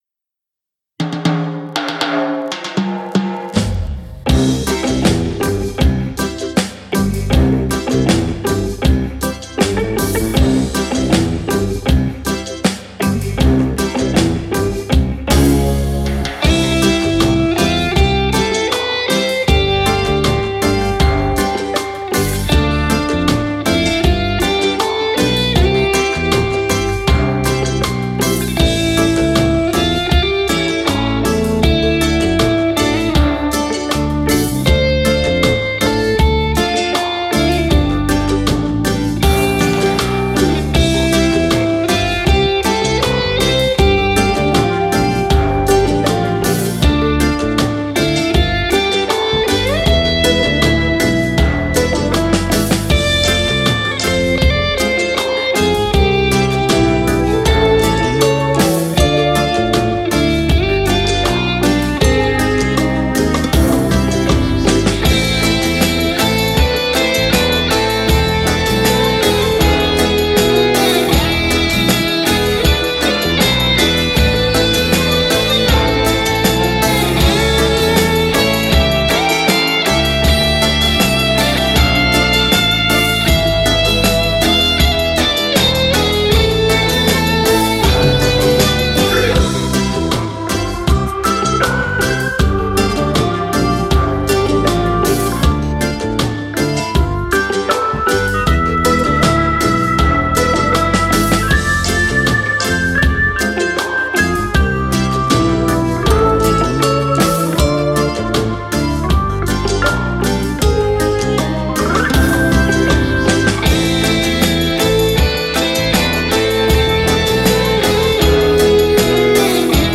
Moderato
24 ballabili per chitarra solista